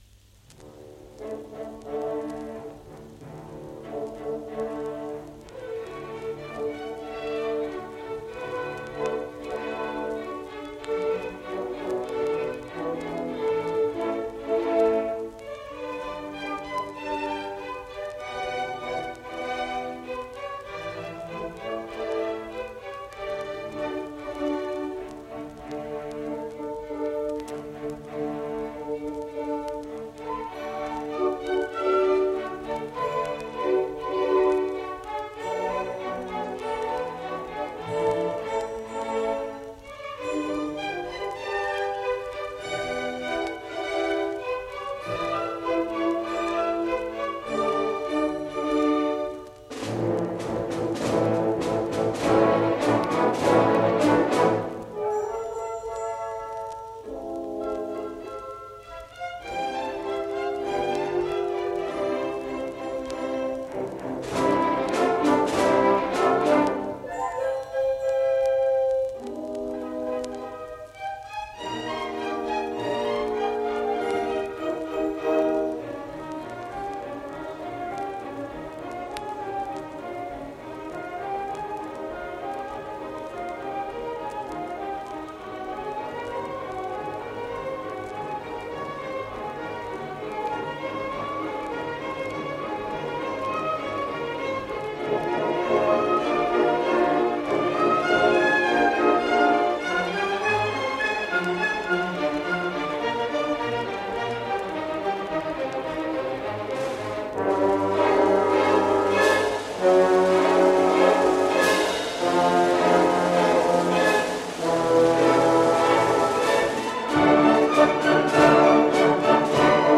Youth Orchestra